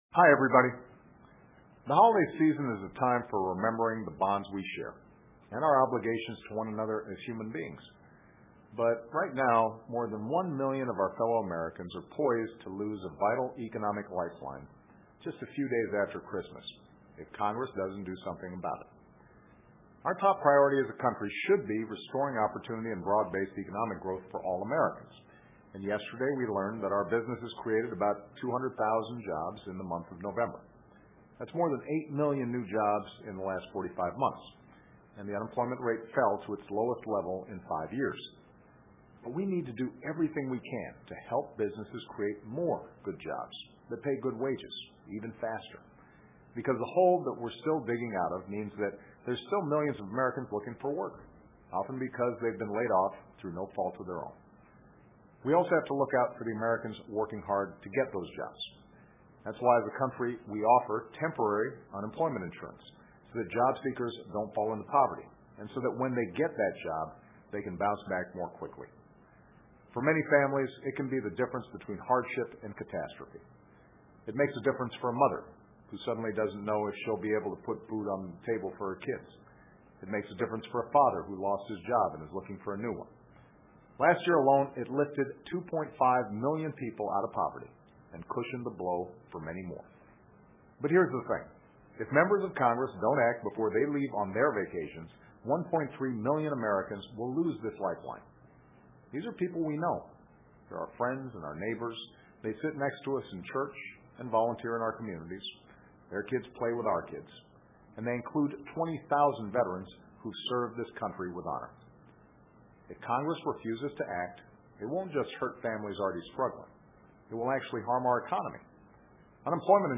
奥巴马每周电视讲话：总体呼吁国会延长失业最低保障政策 听力文件下载—在线英语听力室